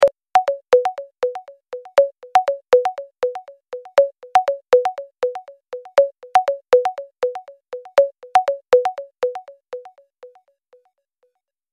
جلوه های صوتی
دانلود صدای تلفن 10 از ساعد نیوز با لینک مستقیم و کیفیت بالا